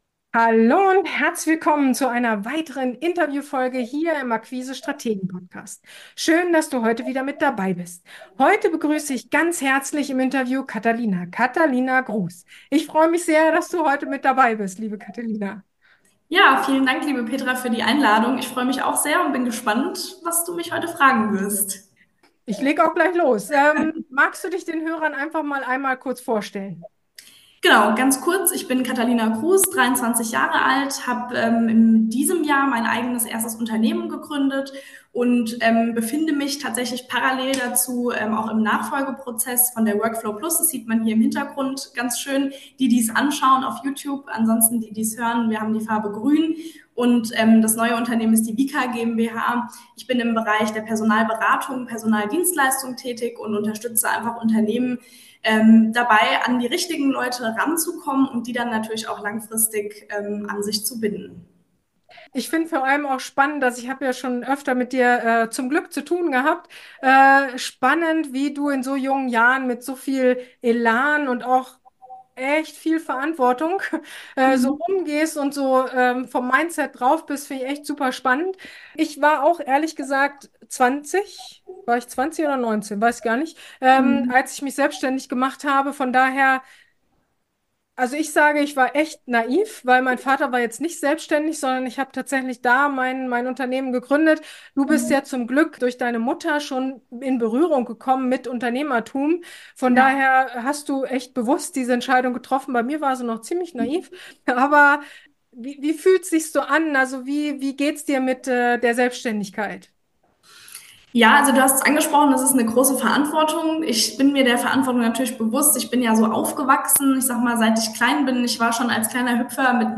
Fachkräftemangel - Gibt es den wirklich? | Interview